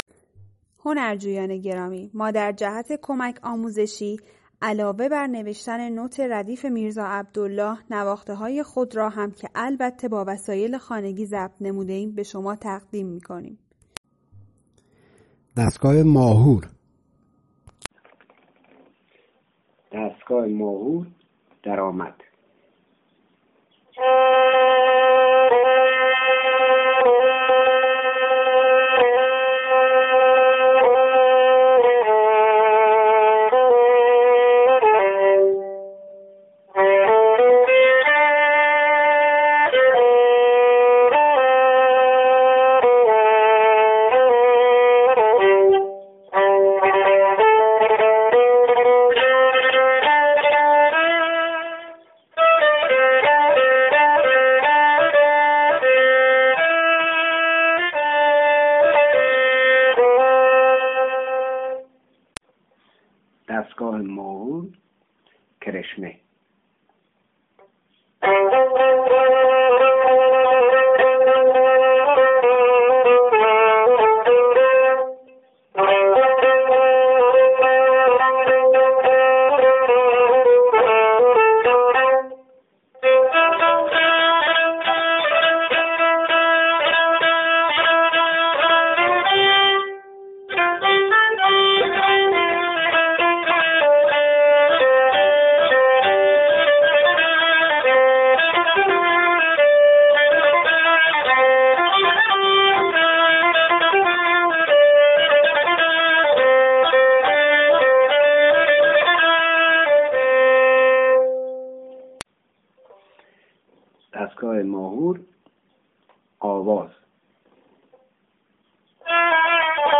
ساز : کمانچه